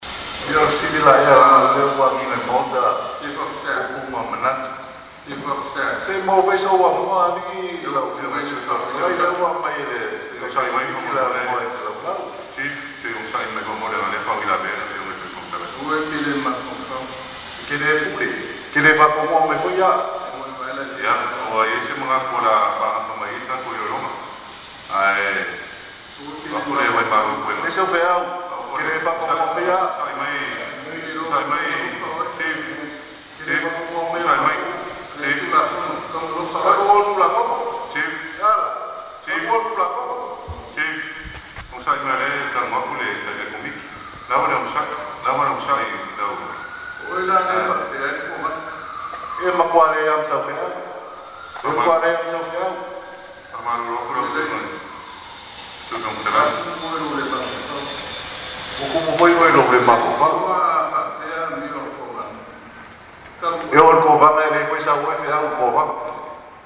The Chief of Staff responded angrily in a loud voice, “e te le pule”, (you don’t have authority), and added, “you do not tell me what to do.”
Here’s part of the heated exchange which was all in Samoan.